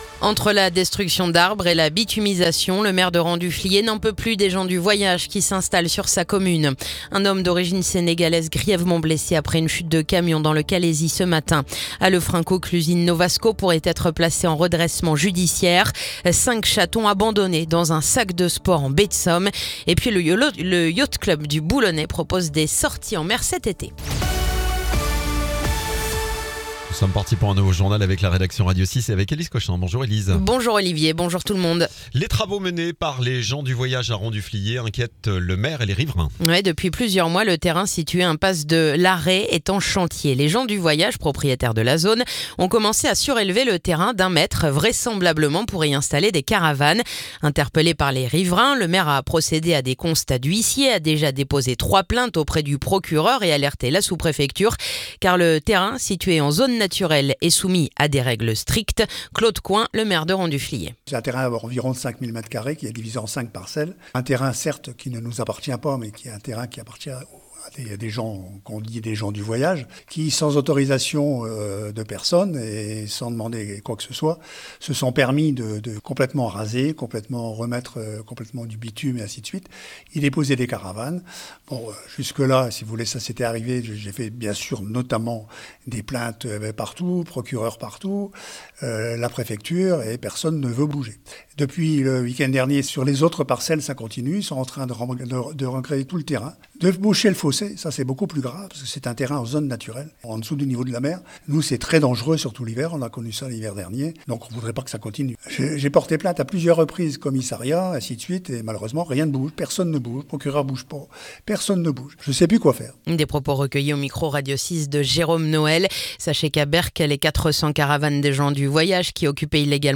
Le journal du mardi 29 juillet